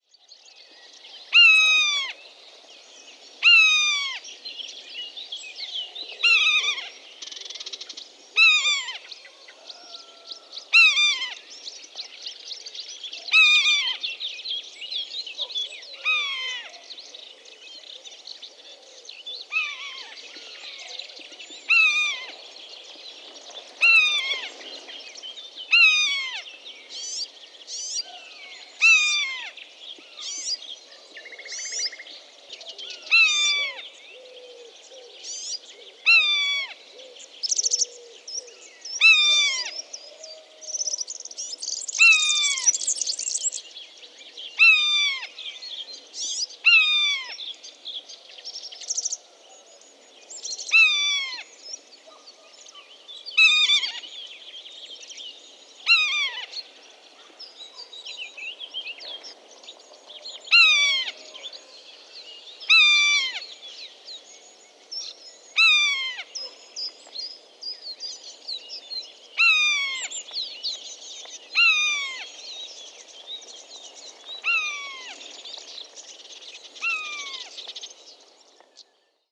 Buizerd
Buizerds hebben een scherpe, kreetachtige roep die je vaak hoort tijdens het baltsen.
Hun geluiden zijn meestal vrij luid en vallen op in het landschap.